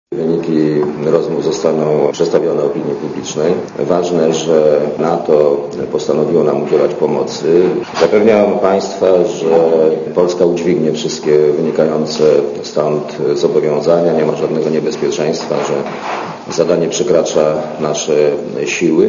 (RadioZet) Źródło: (RadioZet) Mówi Leszek Miller Według premiera to, że będziemy kierować jedną ze stref w Iraku świadczy o wzroście naszego prestiżu na arenie miedzynarodowej.